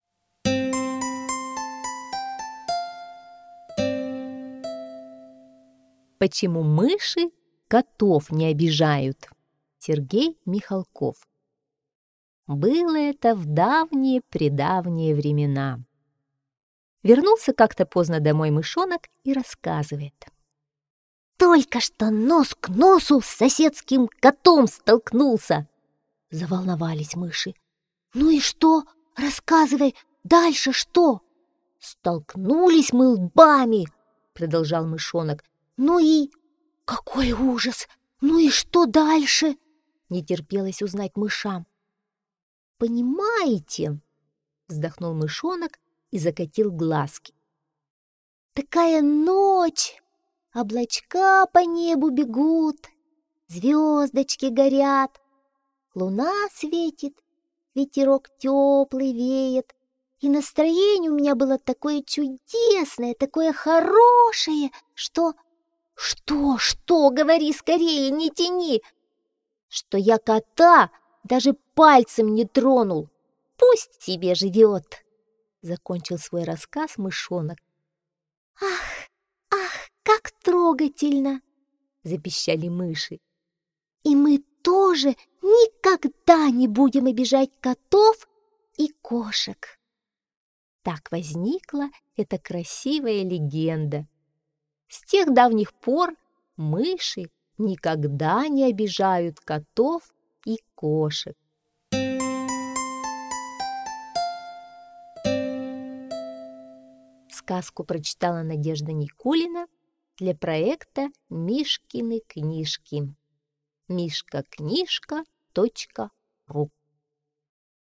Аудиокнига в разделах